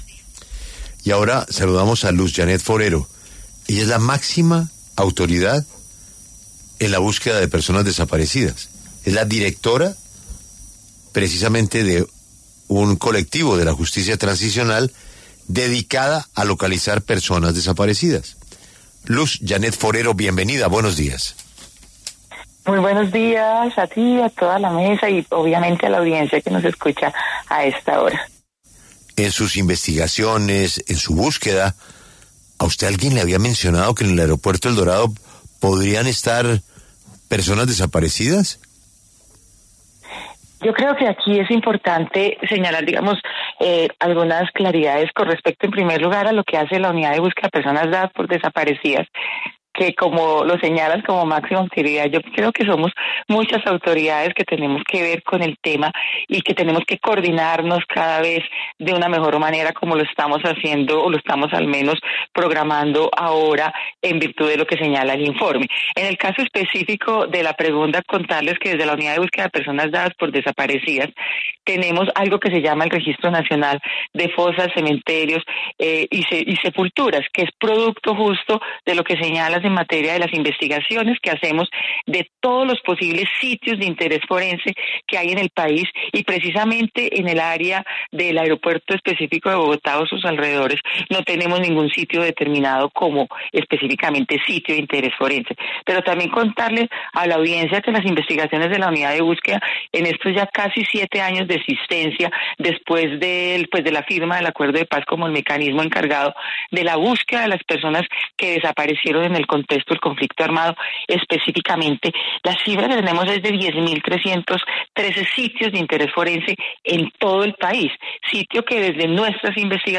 En entrevista con La W, Luz Janeth Forero, directora de la Unidad de Búsqueda de Desaparecidos y máxima instancia de la búsqueda de desaparecidos en Colombia, se refirió al informe del Comité de la ONU contra la desaparición forzada en el que señalan que recibieron información sobre 20.000 desaparecidos almacenados en un hangar del aeropuerto El Dorado.